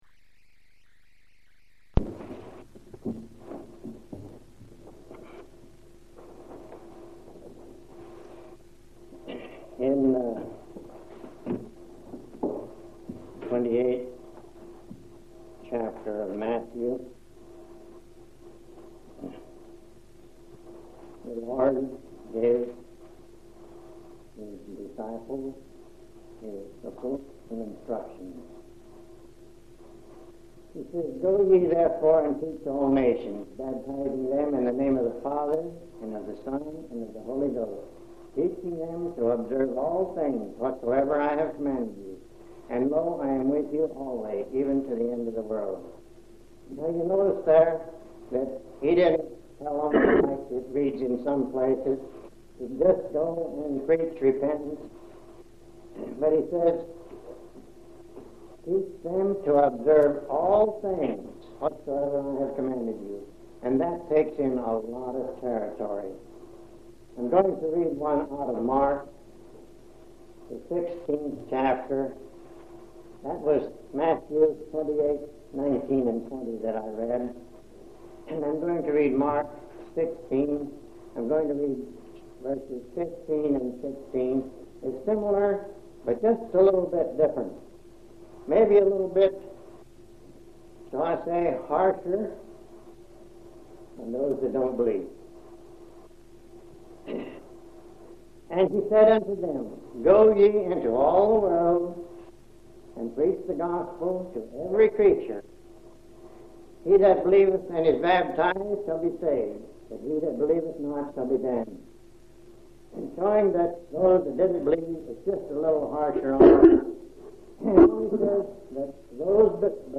3/5/1975 Location: Grand Junction Local Event